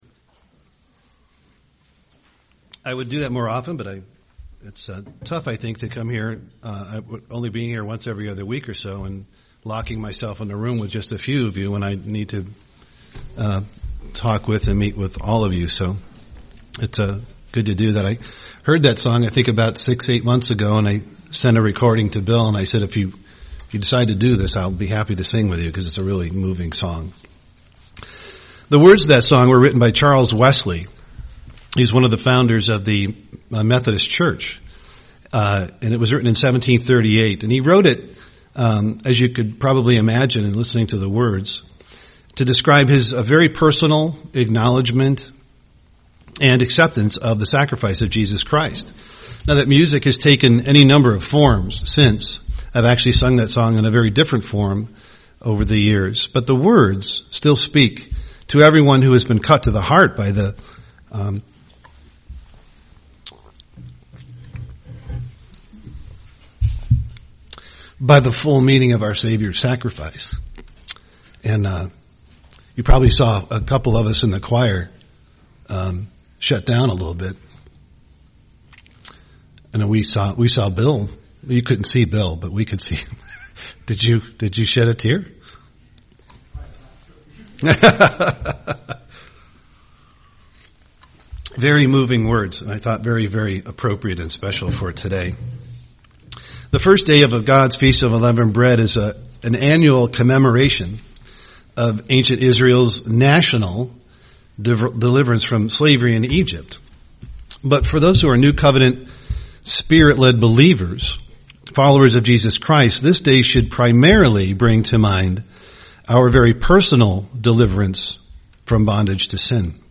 Given in Duluth, MN Twin Cities, MN
UCG Sermon Conversión personal Savior Studying the bible?